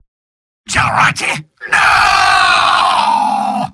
Robot-filtered lines from MvM.
{{AudioTF2}} Category:Spy Robot audio responses You cannot overwrite this file.